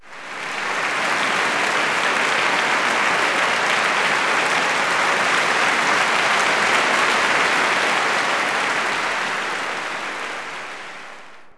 clap_044.wav